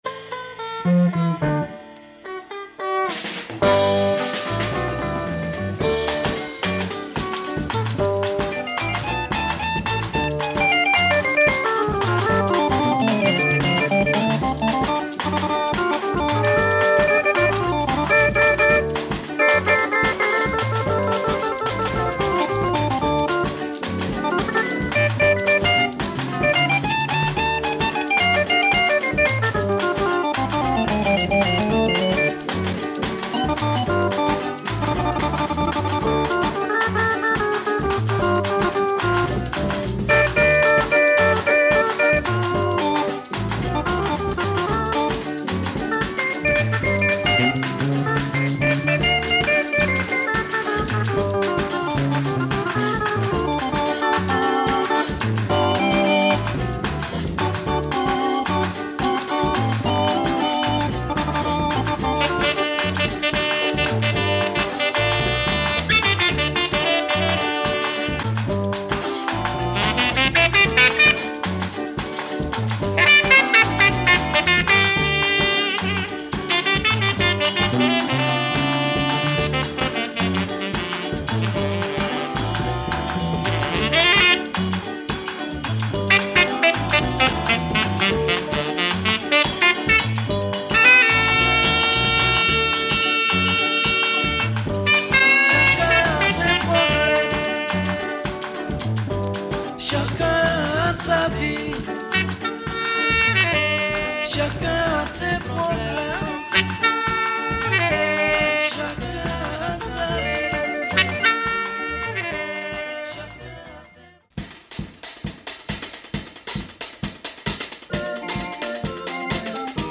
The music is more Malian here...